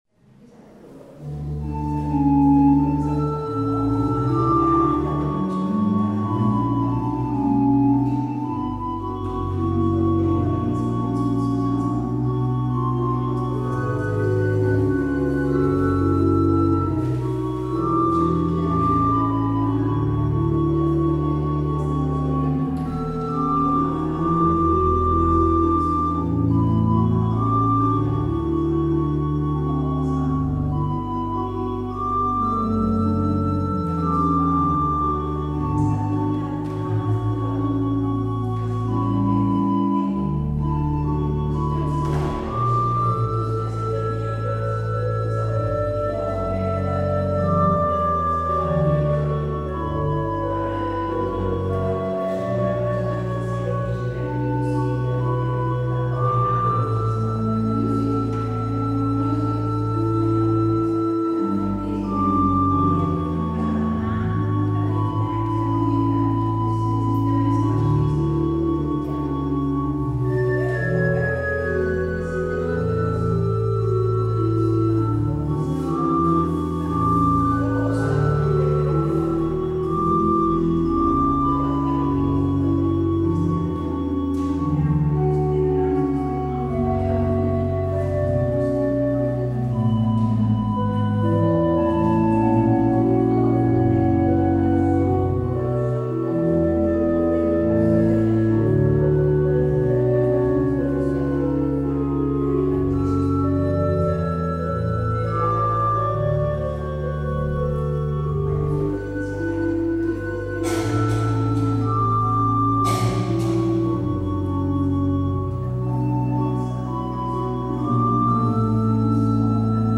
 Luister deze kerkdienst hier terug